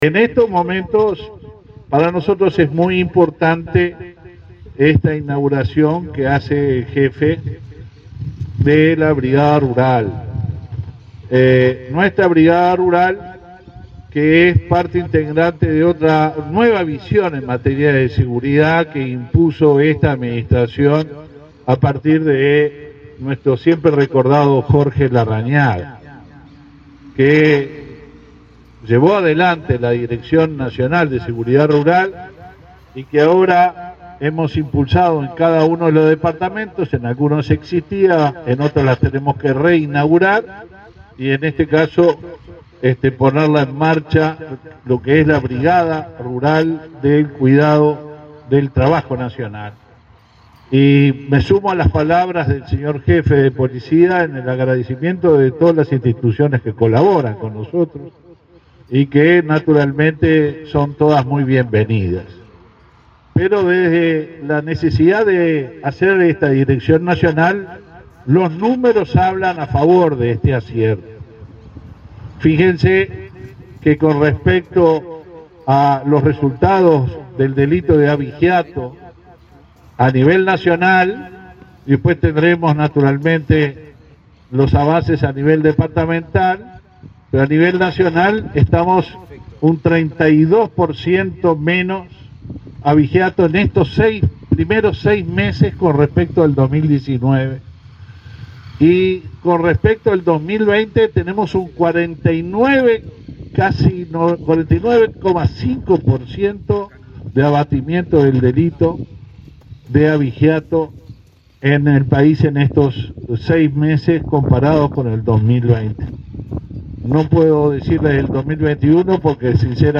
Palabras del ministro del Interior, Luis Alberto Heber
Este viernes 29, el ministro del Interior, Luis Alberto Heber, recorrió varias localidades de Florida e inauguró la Unidad de Seguridad Rural de ese